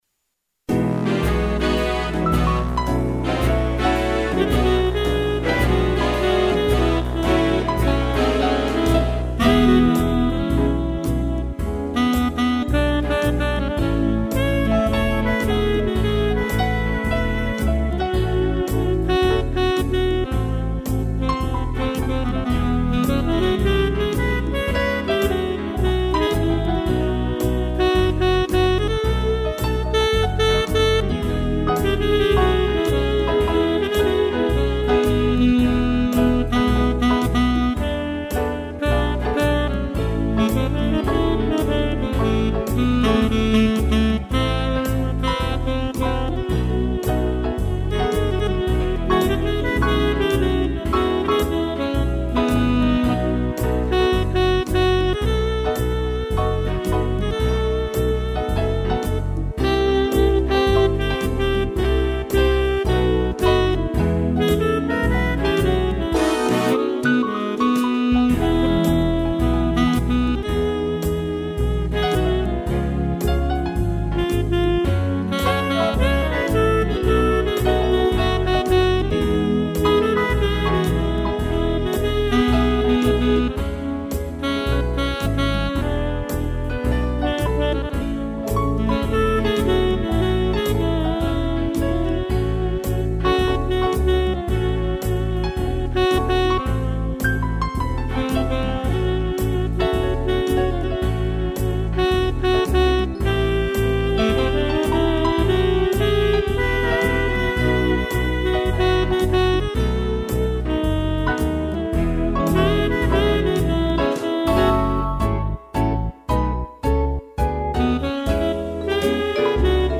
piano e sax
(instrumental)